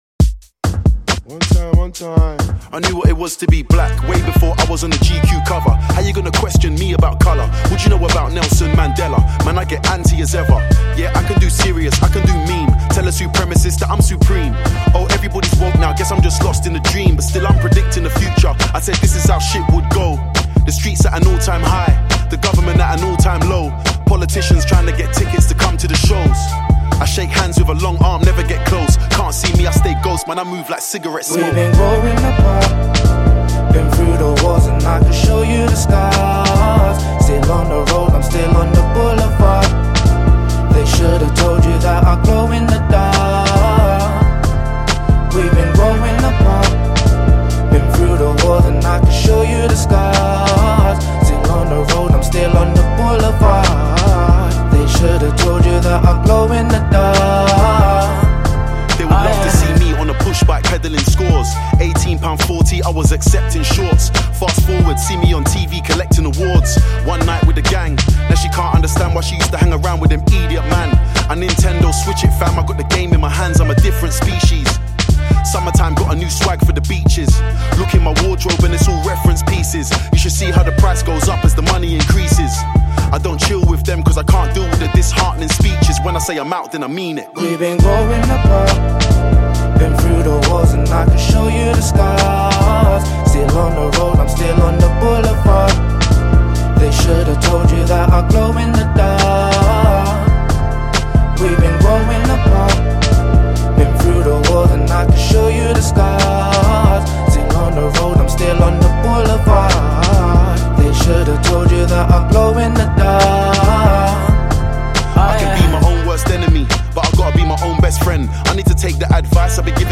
Grime
Grime and Hip-hop
it samples the American classic rap song